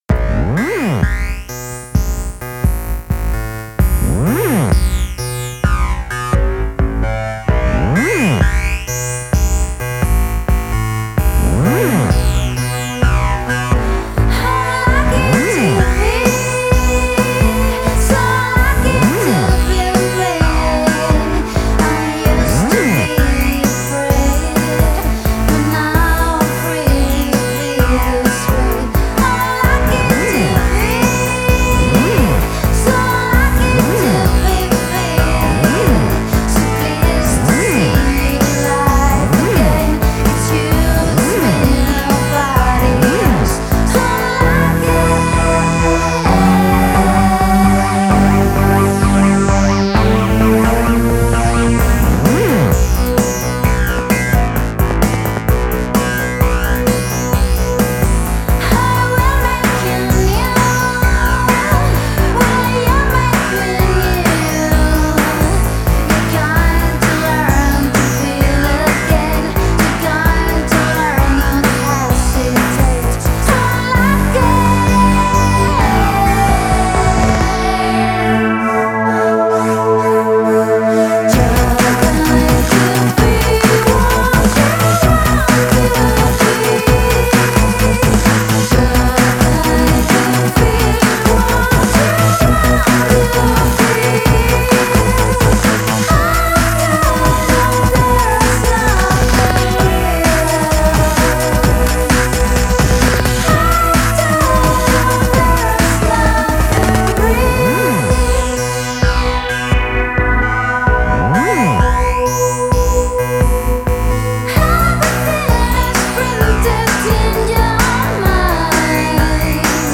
Electro-vintage, si claro !